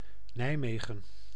Ääntäminen
Ääntäminen Tuntematon aksentti: IPA: /ˈnɛi̯.ˌmeː.ɣə(n)/ Haettu sana löytyi näillä lähdekielillä: hollanti Käännös Erisnimet 1. city of Nijmegen 2.